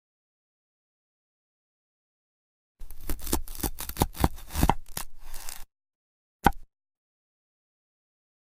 Grand Volcania Cutting ASMR From Sound Effects Free Download